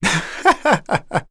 Zafir-Vox_Happy2.wav